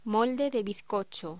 Locución: Molde de bizcocho
voz